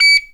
pgs/Assets/Audio/Alarms_Beeps_Siren/beep_17.wav at master
beep_17.wav